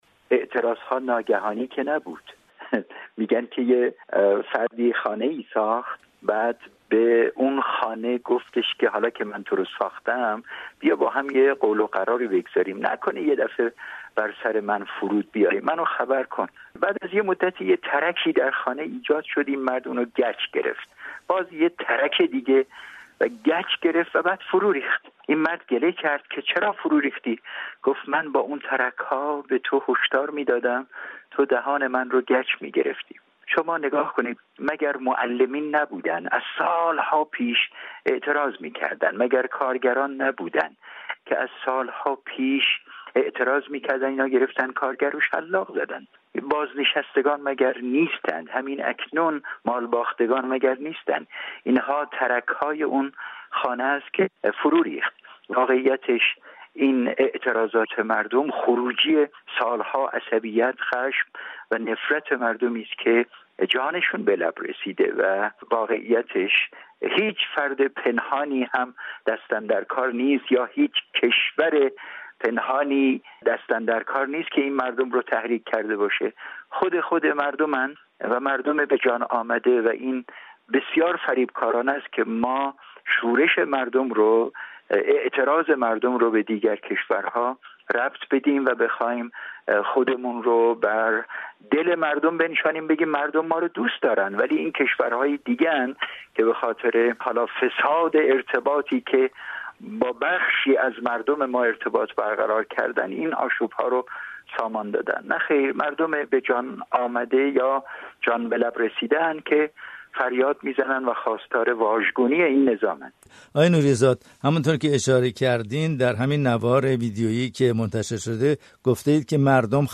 گزارش‌های رادیویی
گفت و گو